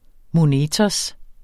Udtale [ moˈneːtʌs ]